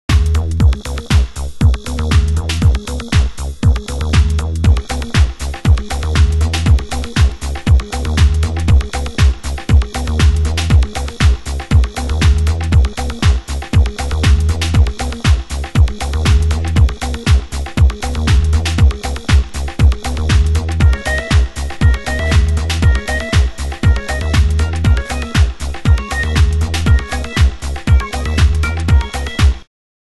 盤質：少しチリノイズ有